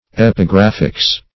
Epigraphics \Ep`i*graph"ics\, n. The science or study of epigraphs.